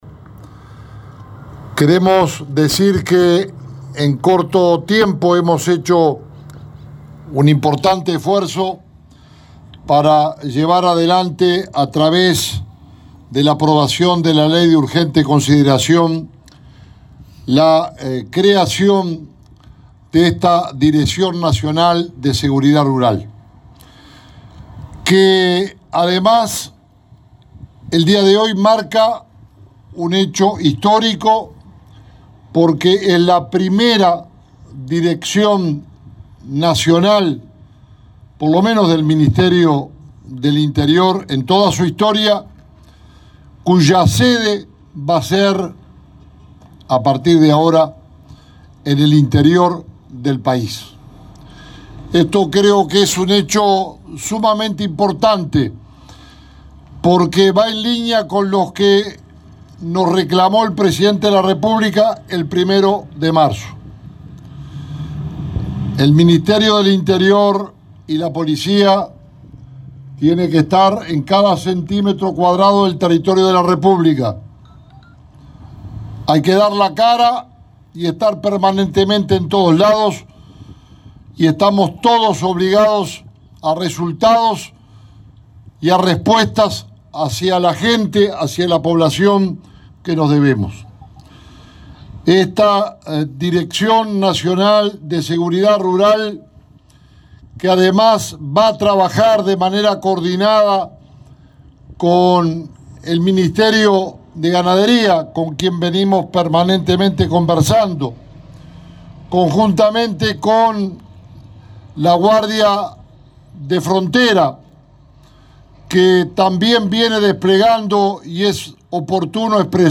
Inauguración de sede de la Dirección Nacional de Seguridad Rural en Florida
El ministro del Interior, Jorge Larrañaga, anunció que la repartición trabajará en forma coordinada con la cartera de Ganadería y la guardia de frontera de Defensa Nacional, el Instituto Nacional de Carnes y las intendencias departamentales, entre otras dependencias.“La seguridad rural es vital en la defensa de la población rural, en la lucha contra abigeato y otras prácticas negativas”, agregó.